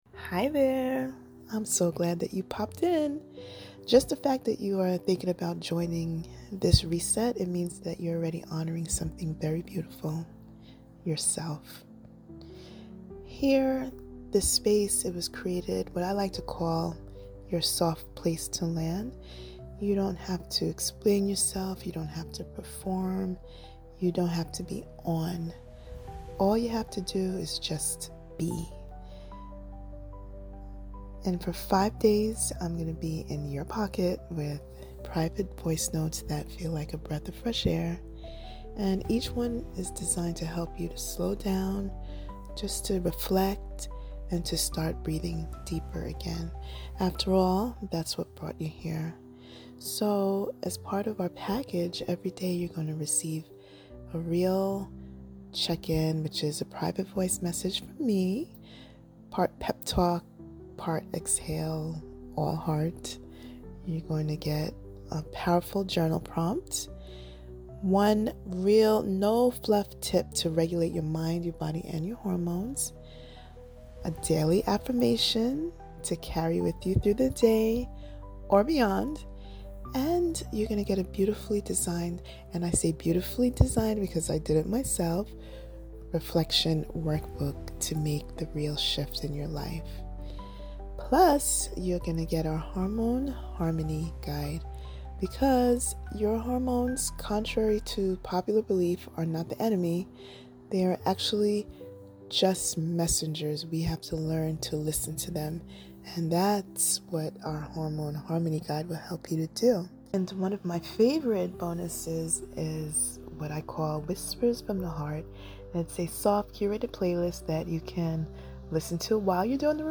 I've recorded a special message for you.. a soft, honest preview of what this reset will feel like — straight from my voice to your heart.